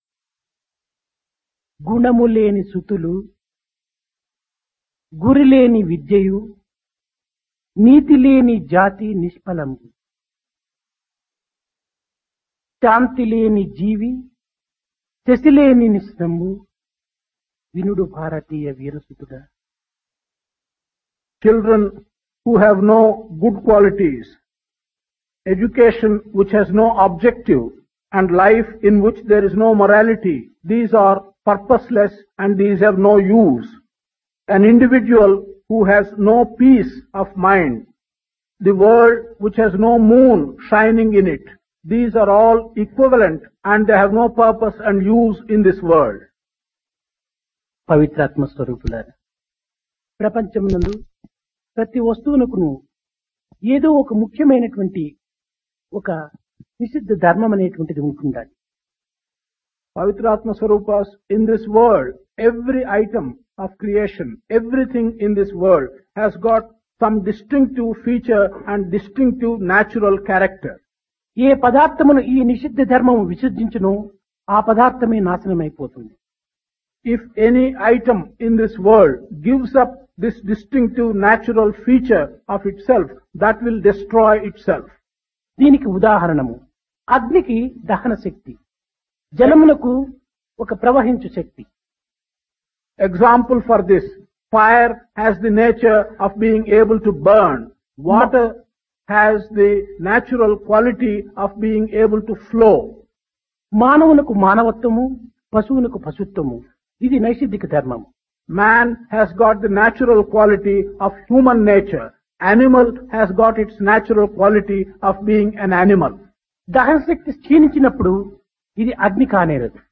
Divine Discourse of Bhagawan Sri Sathya Sai Baba, Summer Showers 1976
Place Ooty Occasion Summer Course 1976 - Indian Culture and Spirituality